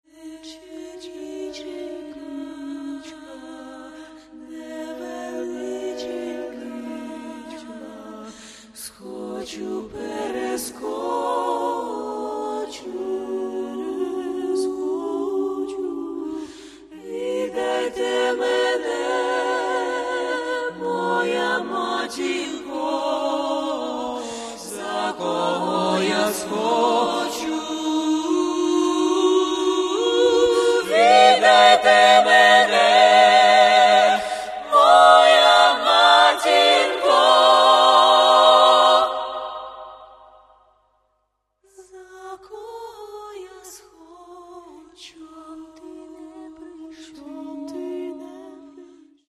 Каталог -> Джаз та навколо -> A-cappella
єдиний жіночий акапельний джазовий квінтет в Україні
Ukrainian folk song